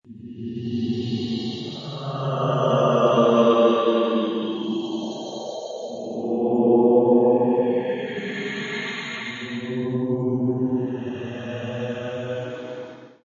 Download Ghost sound effect for free.
Ghost